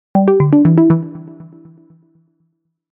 Scifi 10.mp3